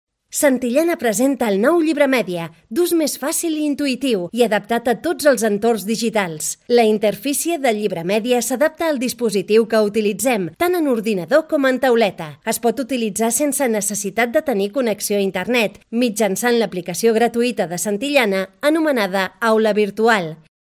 locución para la empresa "Santillana. Vídeo educativo.